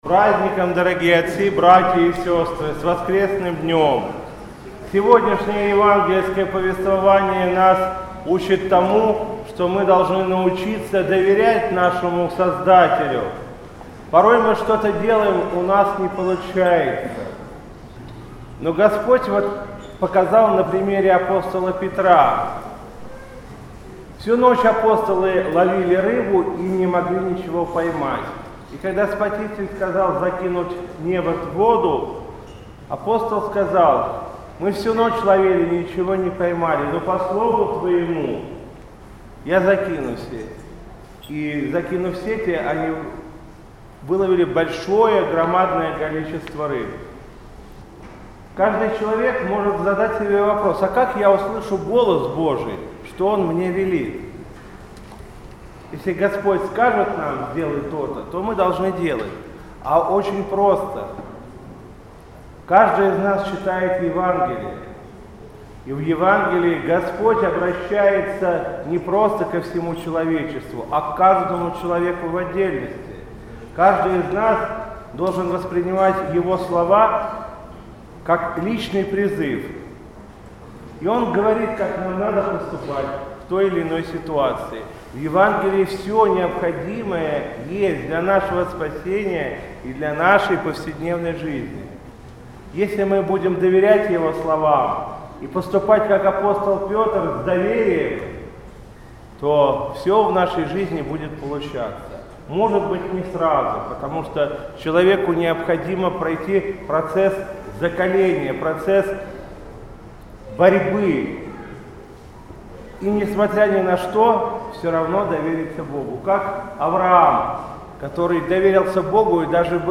В воскресный день 6 октября митрополит Вологодский и Кирилловский Игнатий совершил Божественную литургию в храме Живоначальной Троицы в Хорошеве в Москве.
По окончании богослужения владыка поздравил присутствующих с воскресным днём и обратился с архипастырским словом на тему Евангельского чтения о ловле рыб.